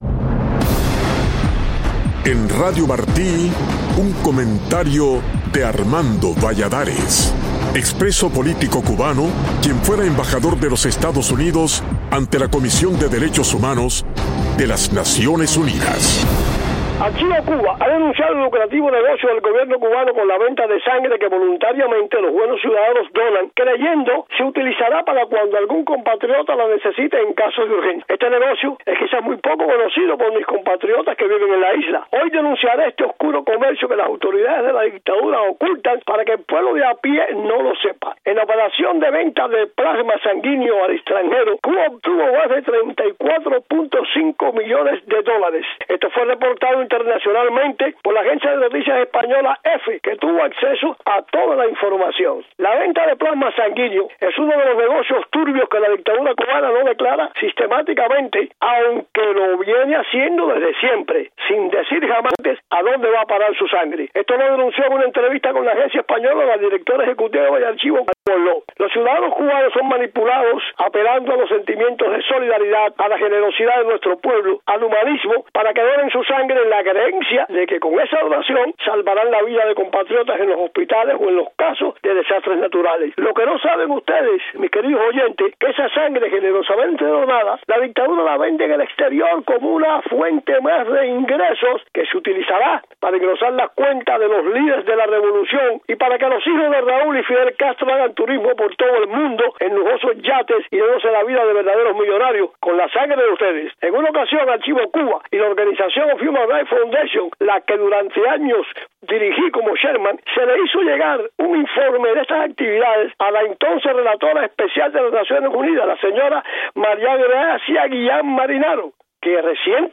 Archivo Cuba ha denunciado el lucrativo negocio del Gobierno cubano con la venta de sangre que voluntariamente los buenos ciudadanos donan creyendo se utilizará cuando algún compatriota la necesite en caso de urgencia. El embajador Valladares denuncia este oscuro negocio en su comentario de hoy.